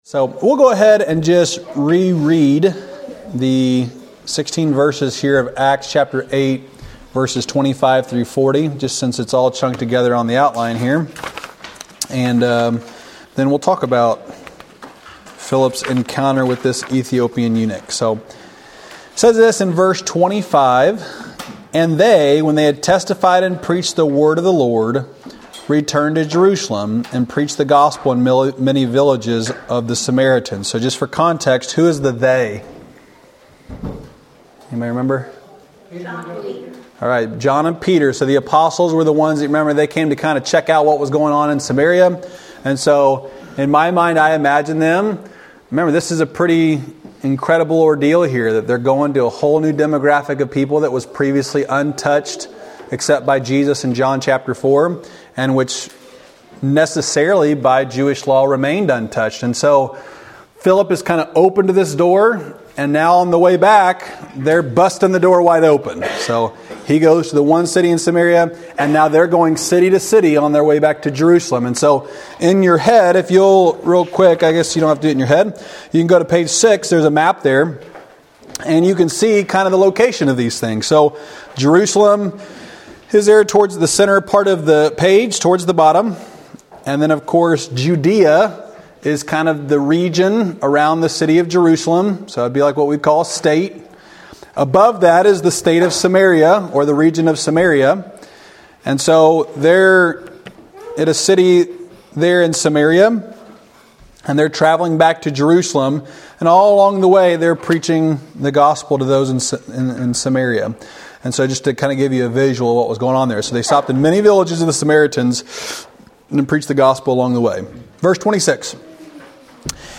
Wednesday night lesson from November 15, 2023 at Old Union Missionary Baptist Church in Bowling Green, Kentucky.